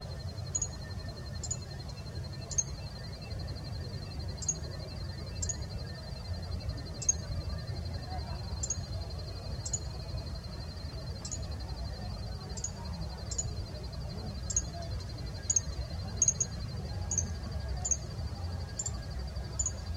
Carpinterito Común (Picumnus cirratus)
El audio es de un ejemplar macho que ahuyentaba a un Veniliornis mixtus que se acercaba a un Espinillo.
Nombre en inglés: White-barred Piculet
Localidad o área protegida: Concordia
Certeza: Fotografiada, Vocalización Grabada
Carpinterito-ahuyentando.mp3